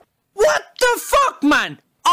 Sound Button WTF Man? Sound Effect Download WTF Man?